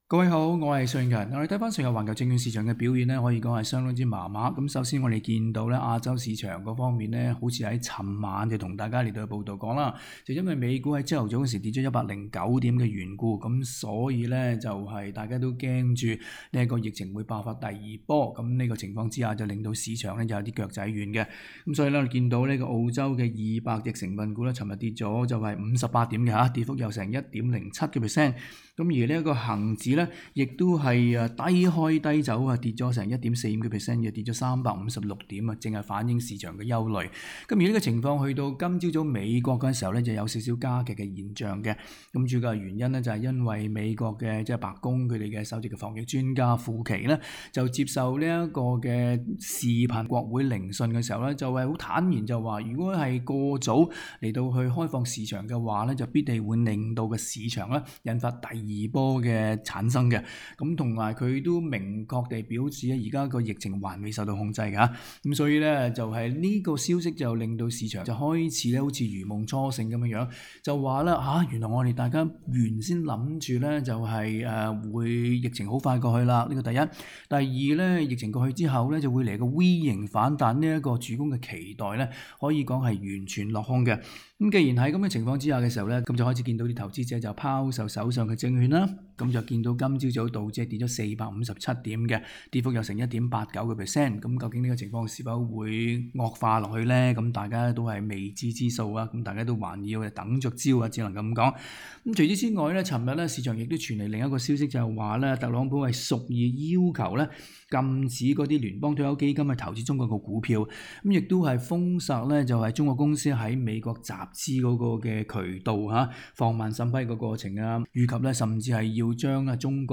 詳情請收聽本文附設錄音訪問内容。